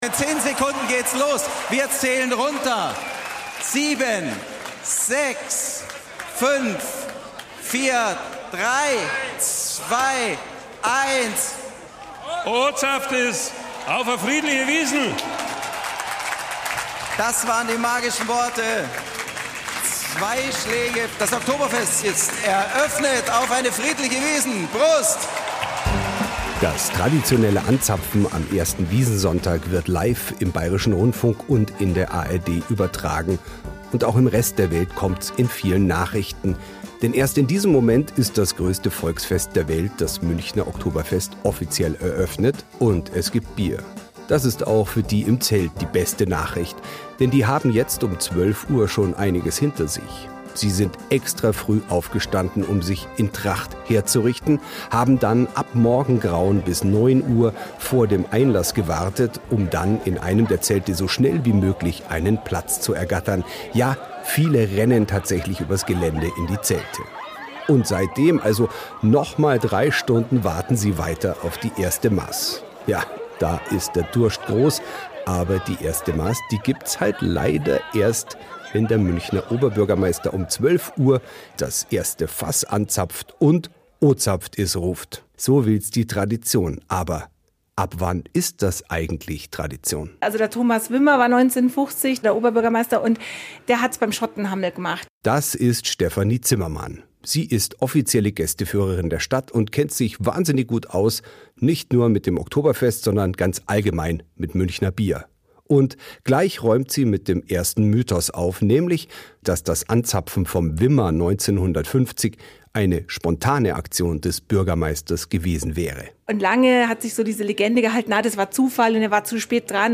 Dabei lernt er nicht nur viel über die Geschichte des größten Volksfests der Welt, er stürzt sich auch mitten ins Getümmel – und wird Zeuge einer Enthauptung.